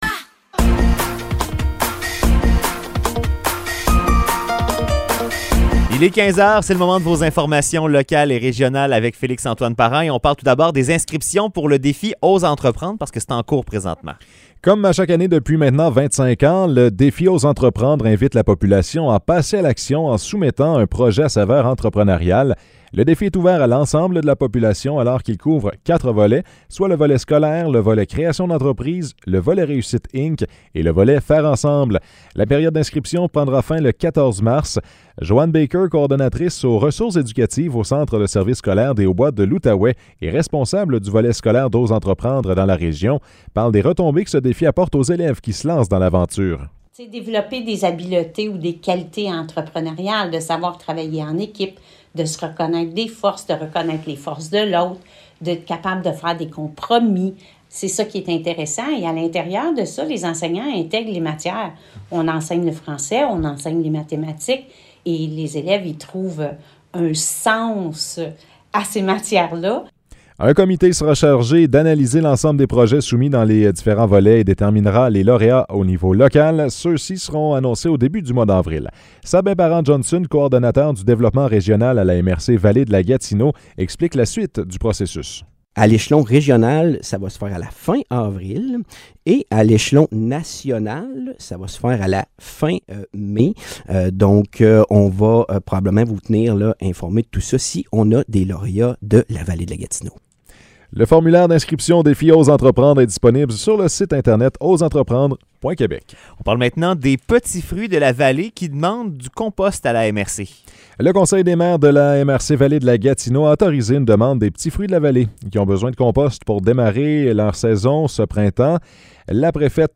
Nouvelles locales - 17 février 2023 - 15 h